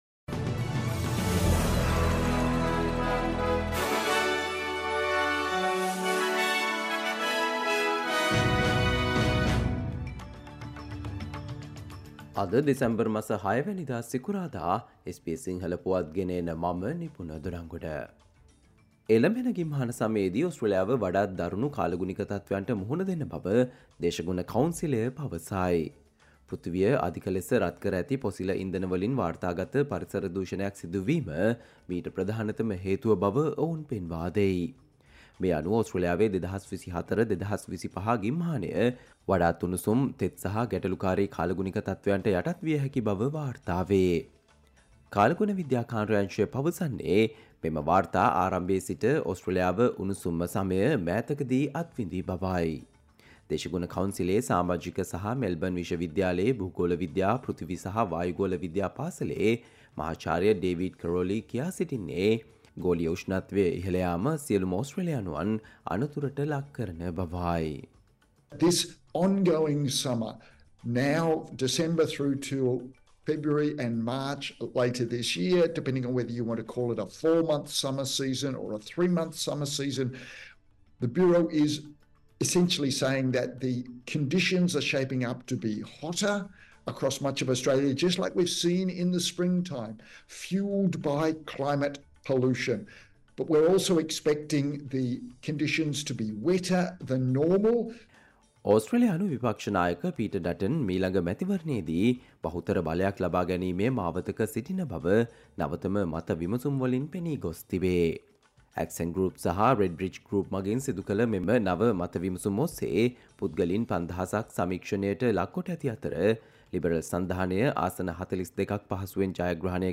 ඕස්ට්‍රේලියාවේ පුවත් සිංහලෙන්, විදෙස් සහ ක්‍රීඩා පුවත් කෙටියෙන් දැනගන්න, සවන්දෙන්න, අද - 2024 දෙසැම්බර් 6 සිකුරාදා SBS සිංහල News Flash